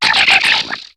Cri de Maskadra dans Pokémon HOME.